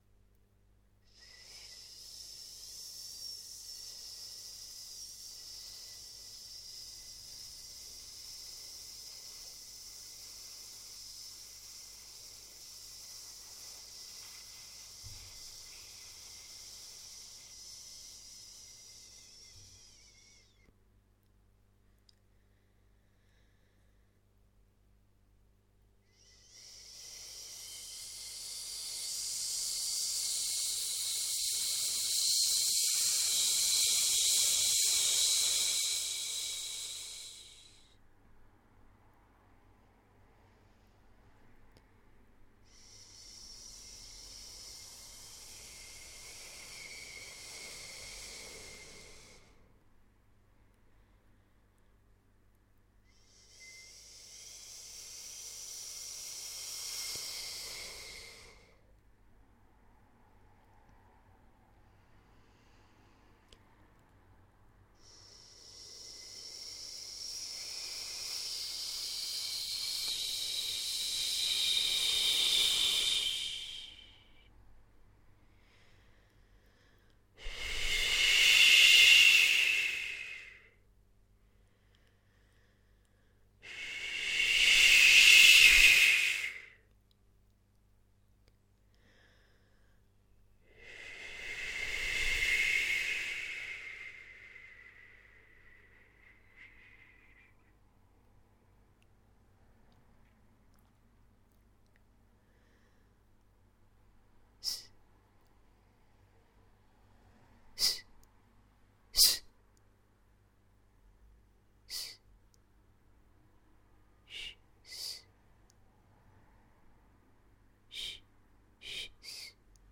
Manifesto: White noise improvisation
Improvised sounds for the fourth audio section of Manifesto (pre-Max/MSP manipulation)